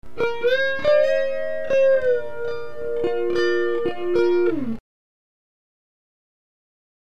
Oscillator = sine
Oscillator Freq = 0.5 Hz
Delay time = 30 ms
Depth = 1 ms
Wet Gain = 1
Add Chorus
clip16_6s_chorus1.mp3